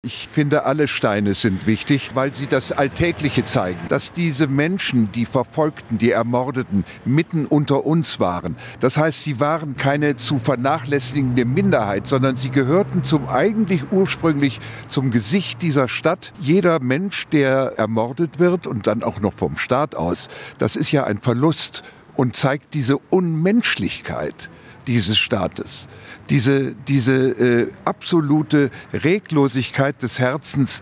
MITSCHNITT AUS DER SENDUNG